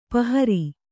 ♪ pahari